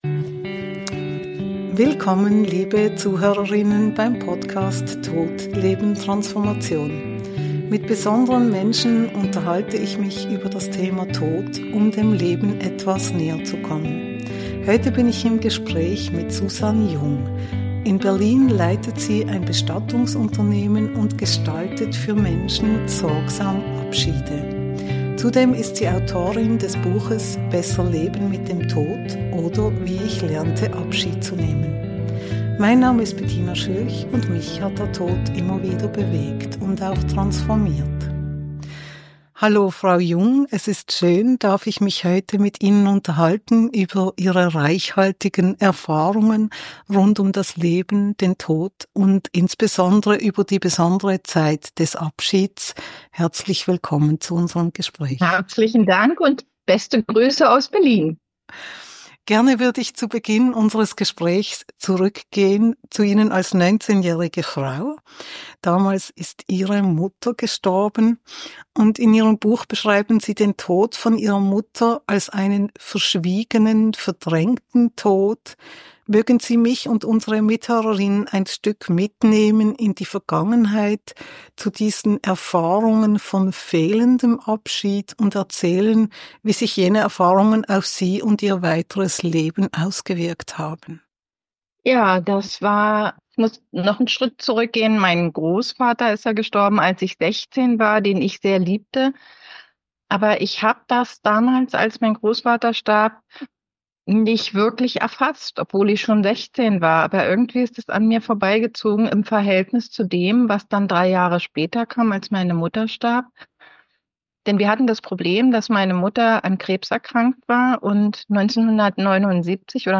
im Gespräch mit der Bestatterin